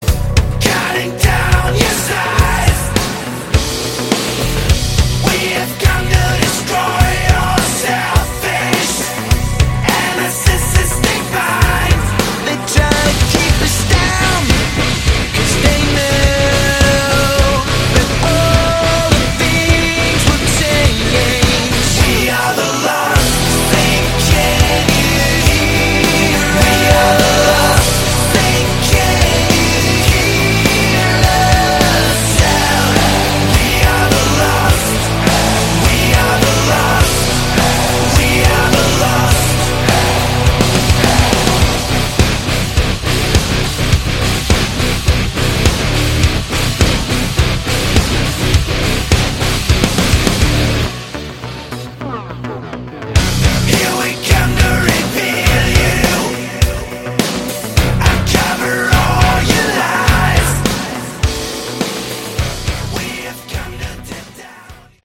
Category: Modern Synth Hard Rock